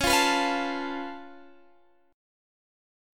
Db+7 Chord
Listen to Db+7 strummed